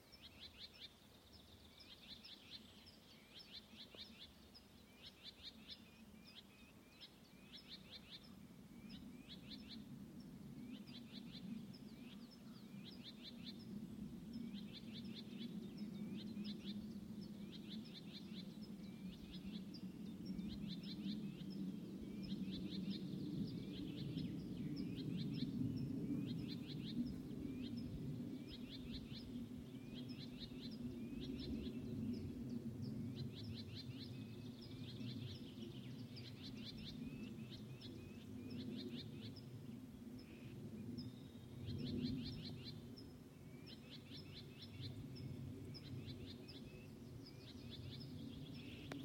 Birds -> Warblers -> 1
Whitethroat, Curruca communis
StatusAgitated behaviour or anxiety calls from adults